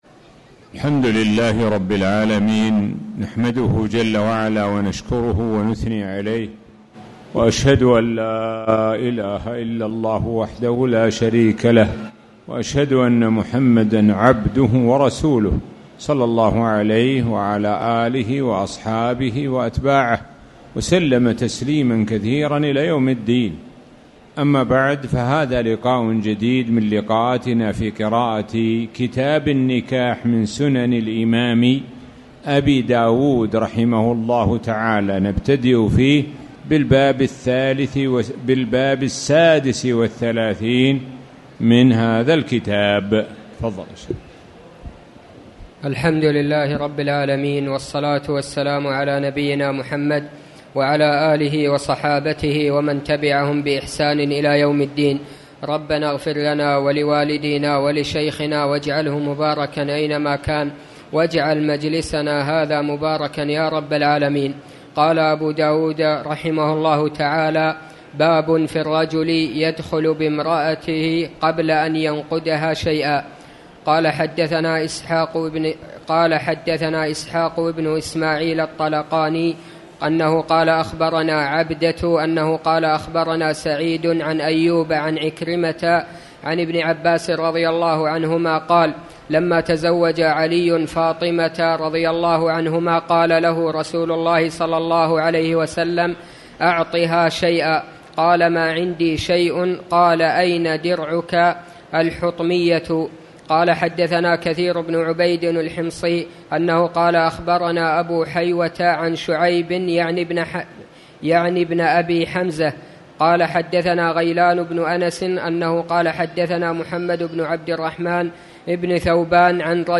تاريخ النشر ٢٧ شوال ١٤٣٨ هـ المكان: المسجد الحرام الشيخ: معالي الشيخ د. سعد بن ناصر الشثري معالي الشيخ د. سعد بن ناصر الشثري كتاب النكاح